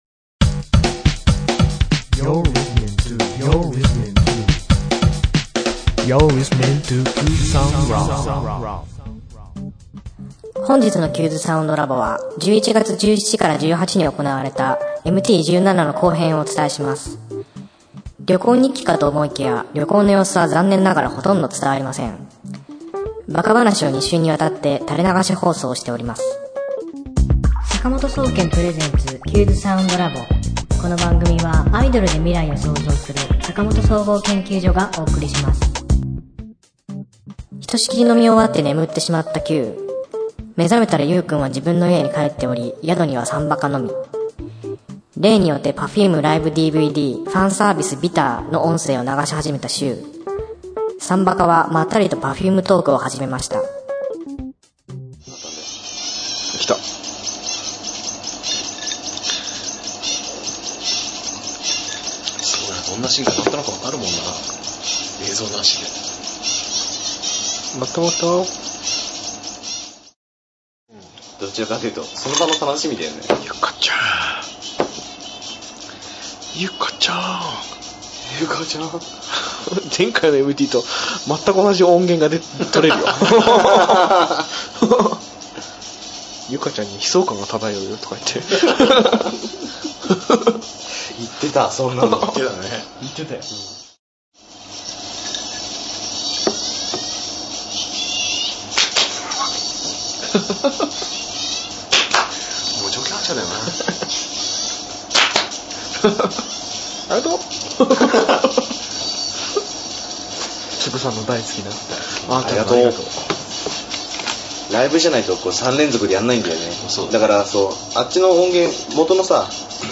今週のテーマ：お馴染み参馬鹿の珍道中～後編～ MTとはマニュアル車運転部のこと。旅行日記かと思いきや、旅の様子ではなく、単なるバカ話の垂れ流し放送です。